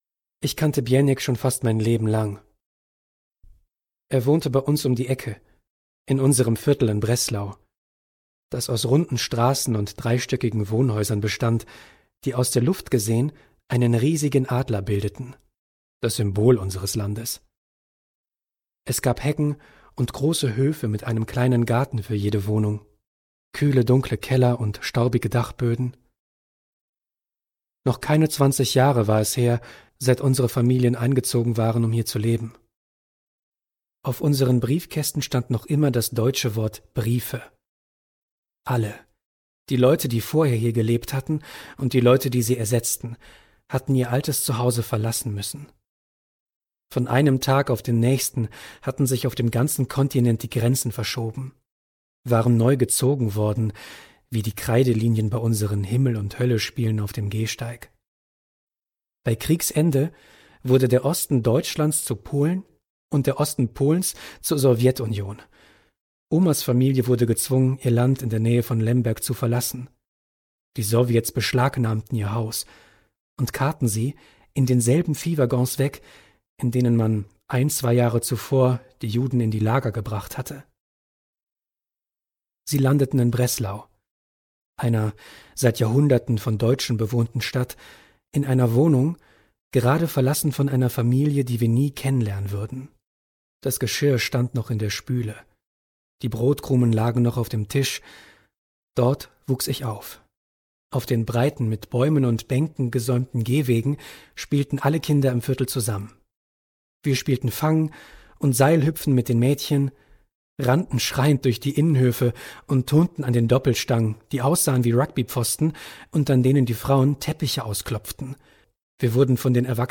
2021 | Ungekürzte Lesung